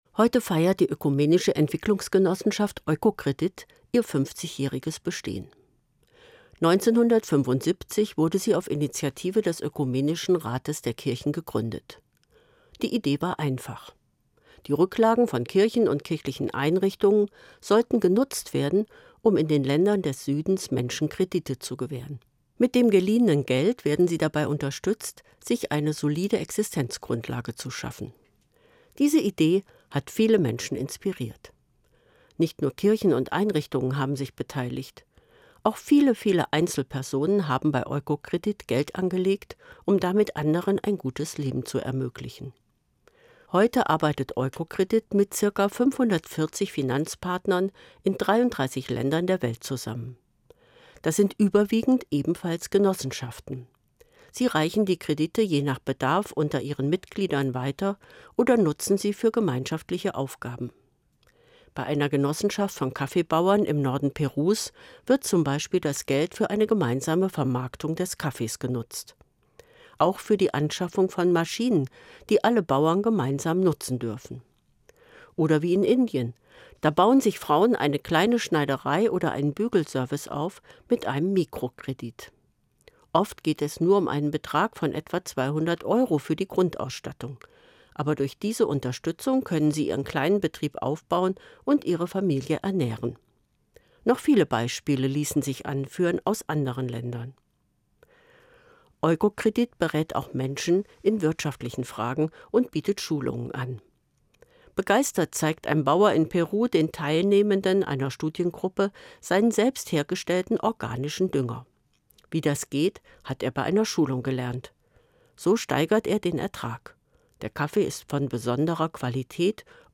Evangelische Pfarrerin, Kassel